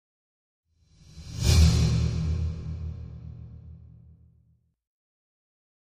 Drum Metallic Hit - Final Hits Dangerous - C